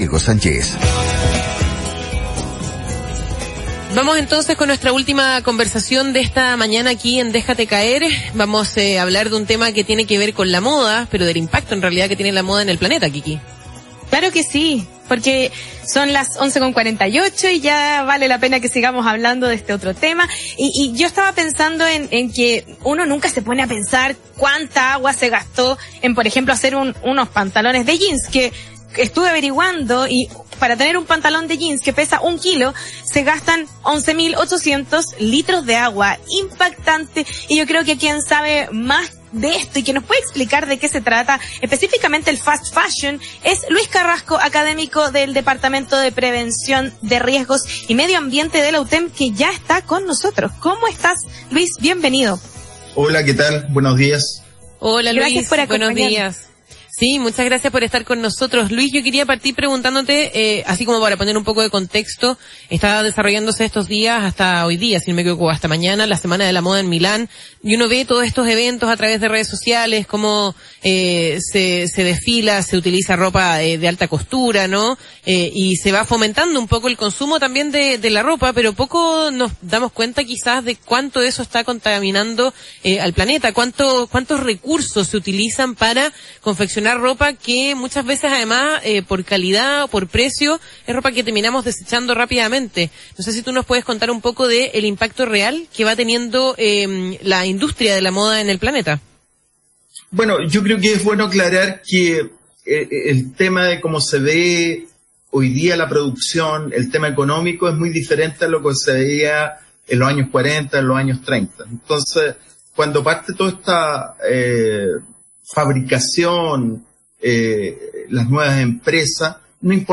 fue entrevistado acerca del impacto de la moda en el planeta.